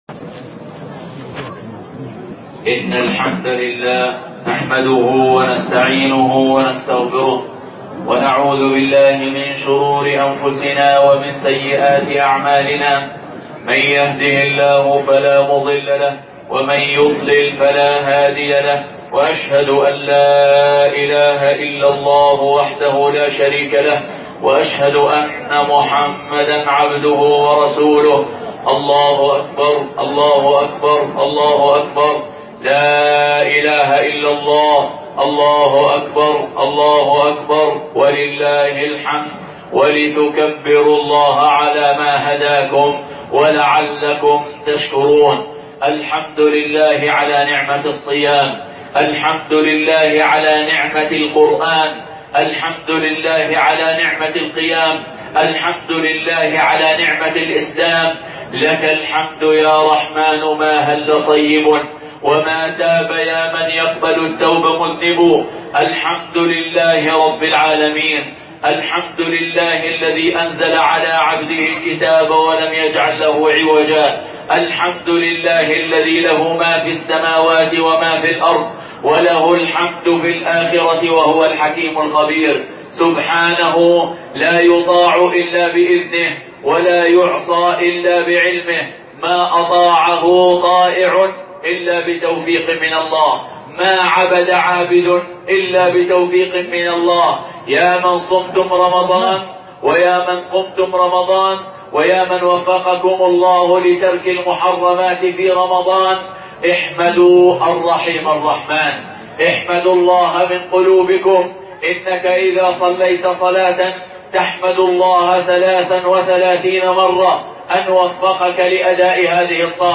خطبة عيد الفطر 1439
خطبة-عيد-الفطر-1.mp3